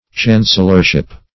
\Chan"cel*lor*ship\ (ch[.a]n"s[e^]l*l[~e]r*sh[i^]p)
chancellorship.mp3